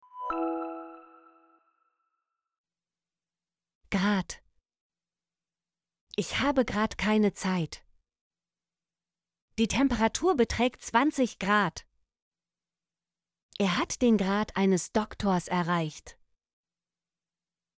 grad - Example Sentences & Pronunciation, German Frequency List